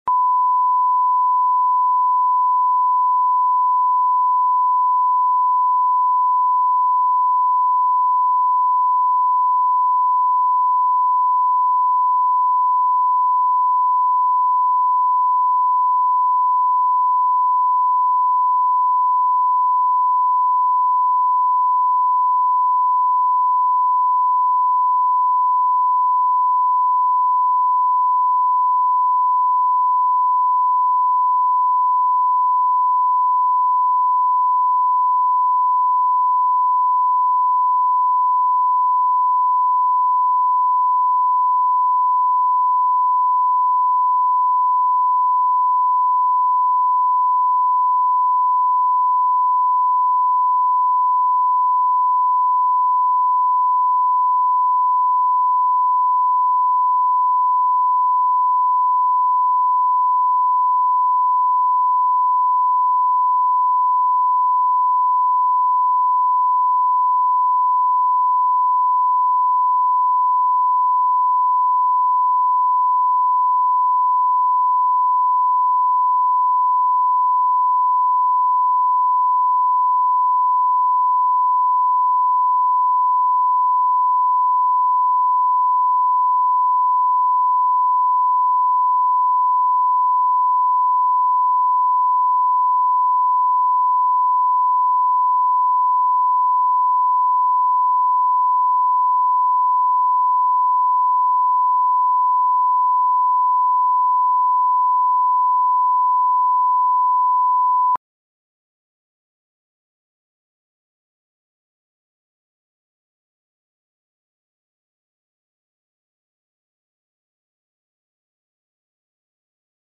Аудиокнига Я любила свою жизнь | Библиотека аудиокниг